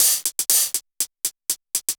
Index of /musicradar/ultimate-hihat-samples/120bpm
UHH_ElectroHatB_120-02.wav